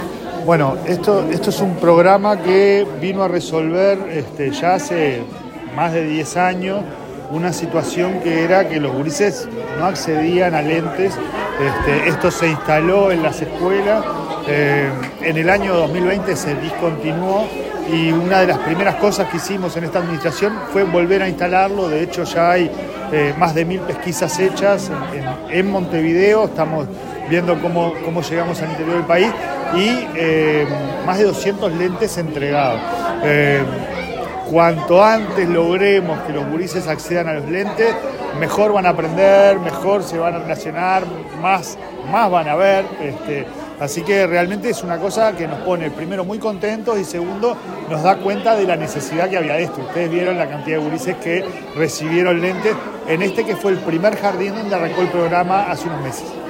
Declaraciones del presidente de ANEP, Pablo Caggiani
Caggiani_Prensa.mp3